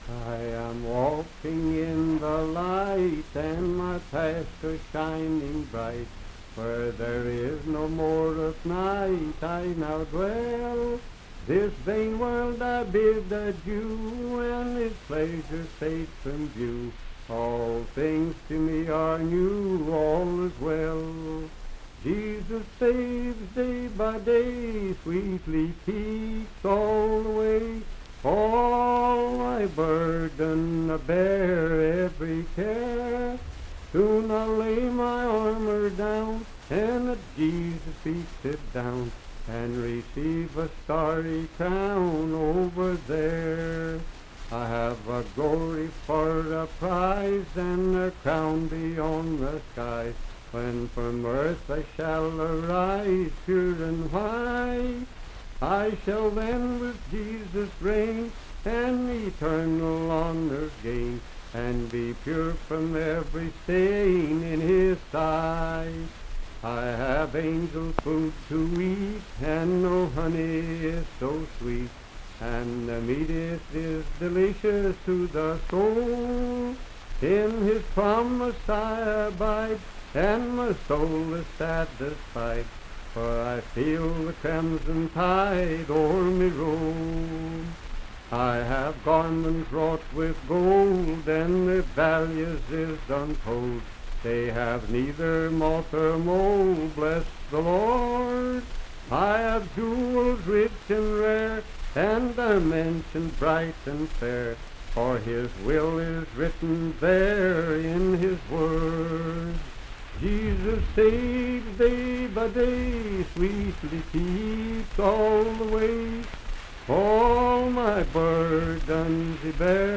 Unaccompanied vocal music
Hymns and Spiritual Music
Voice (sung)
Franklin (Pendleton County, W. Va.), Pendleton County (W. Va.)